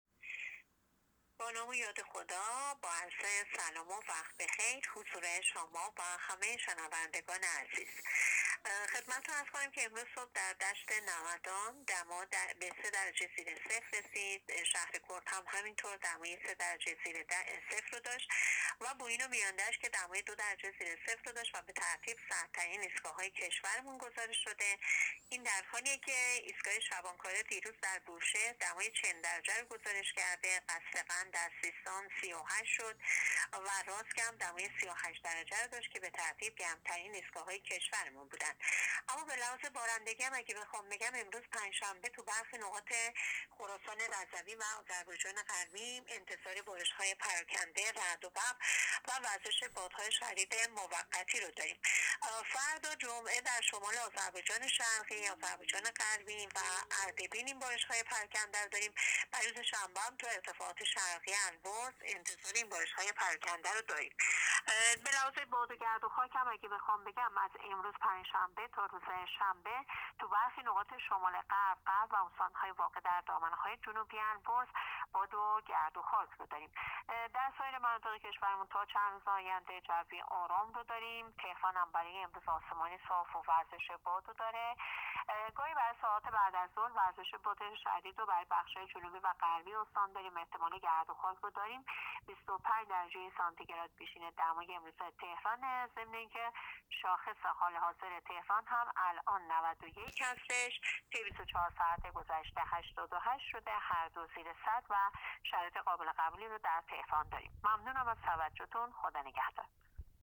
گزارش رادیو اینترنتی پایگاه‌ خبری از آخرین وضعیت آب‌وهوای یکم آبان؛